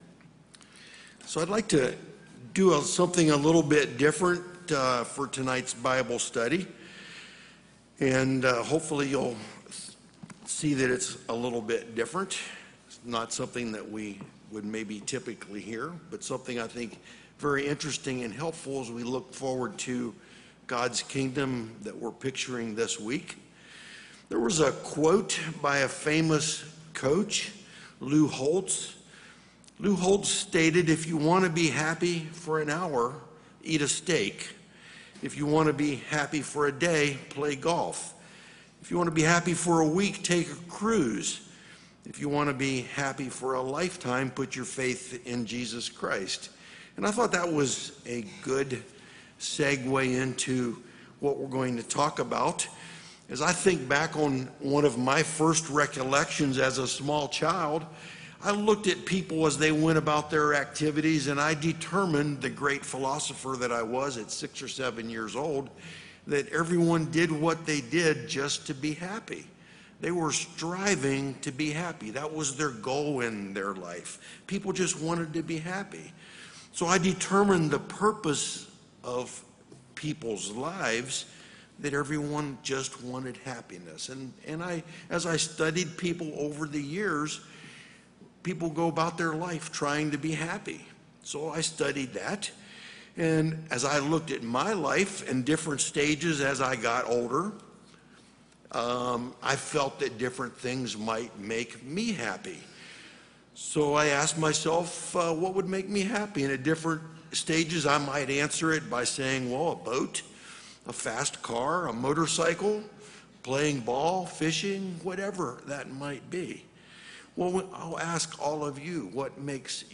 This sermon was given at the Lake Junaluska, North Carolina 2021 Feast site.